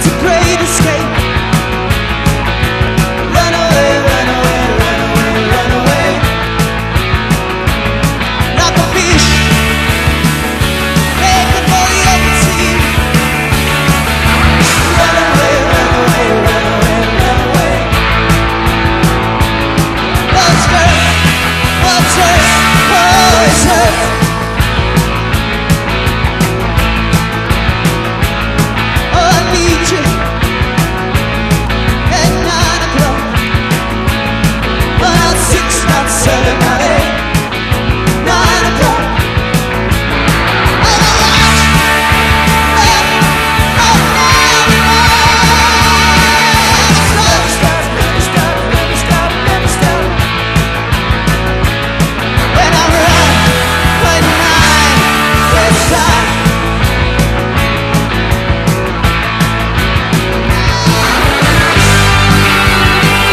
¥1,280 (税込) ROCK / 80'S/NEW WAVE.
LOVERS ROCK
スキャット飛び散る高速エセ・スウィング＆驚きのラヴァーズ・ロックまで！